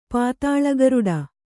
♪ pātāḷa garuḍa